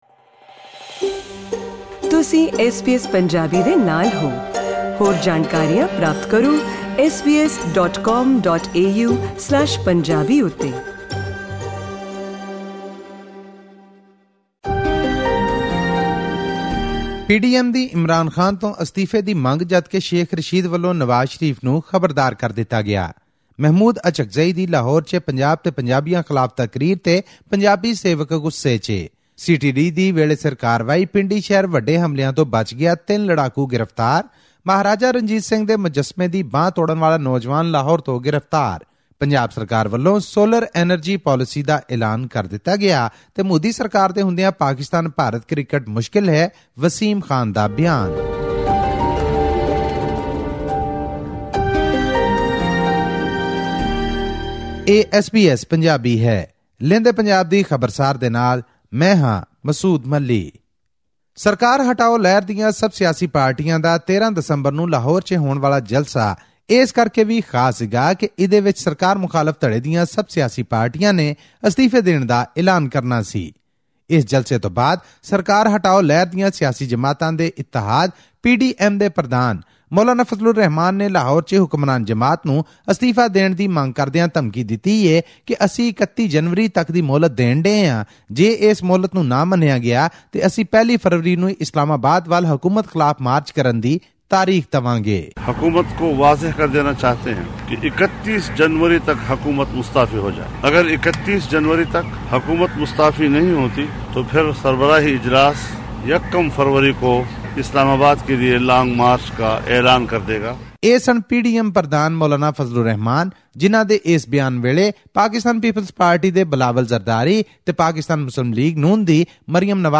In our weekly news report from Pakistan’s Punjab province, PDM (Pakistan Democratic Movement) president Maulana Fazlur Rehman, alongside PML-N's Maryam Nawaz and PPP chief Bilawal Bhutto-Zardari issued an ultimatum to Prime Minister Imran Khan to quit by January 31 or face intense nation-wide protests.